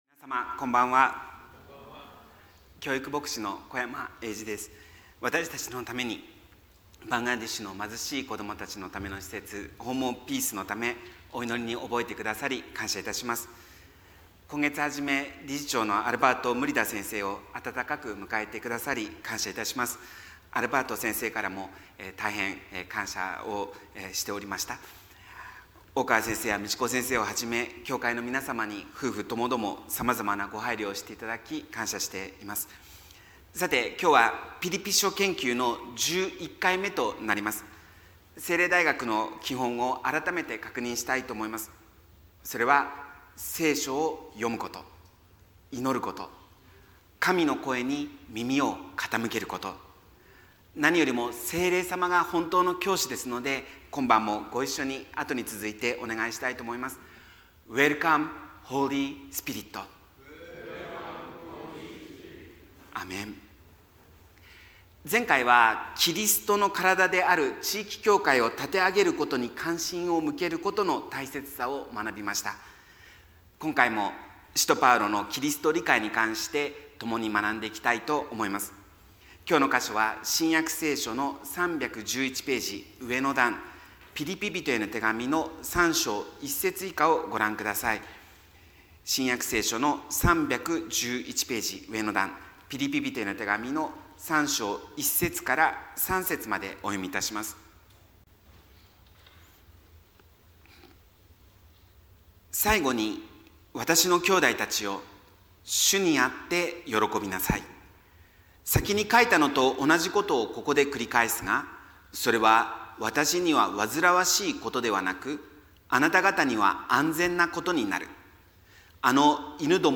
メッセージ